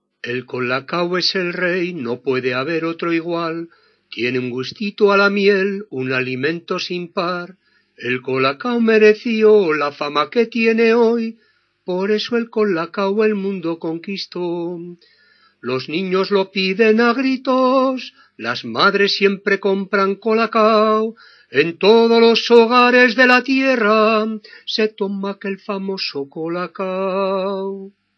a ritme de txa-txa-txa